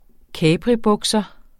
Udtale [ ˈkæːpʁi- ]